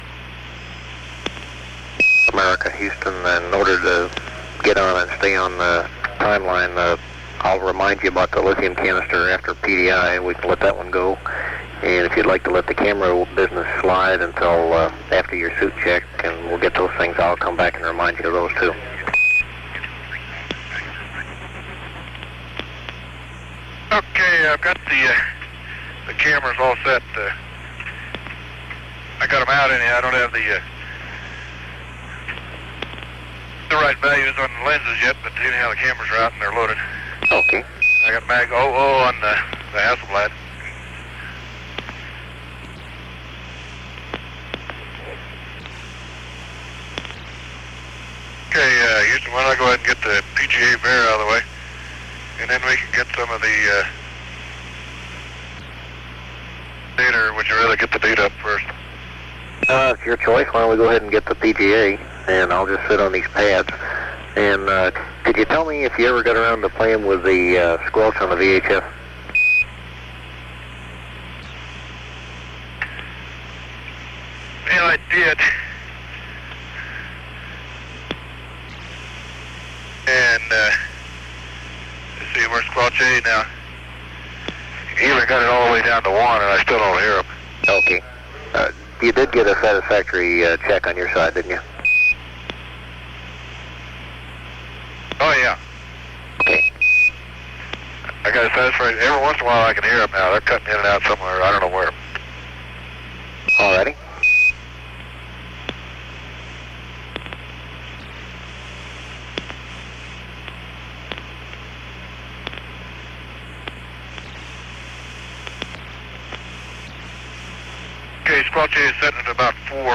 It appears that long quiet periods have been deleted, probably by a process of copying from one tape machine to another.